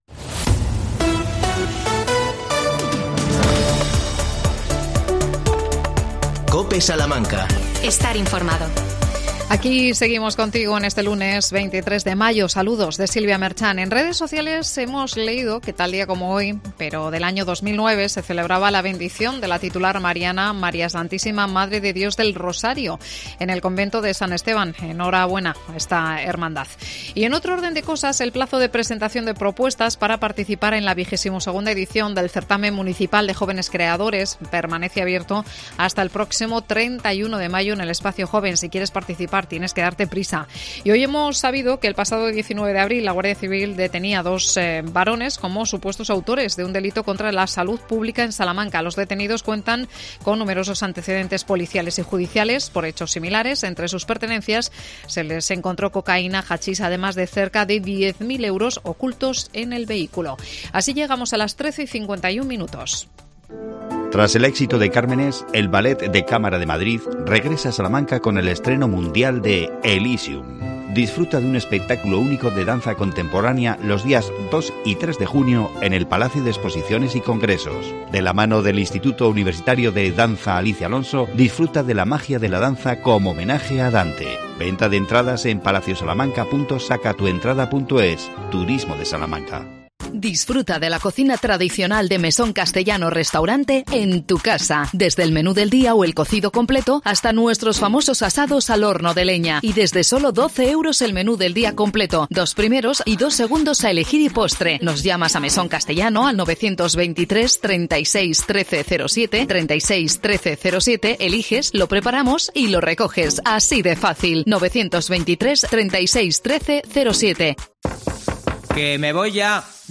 AUDIO: 40 años de ASPAS Salamanca. Entrevistamos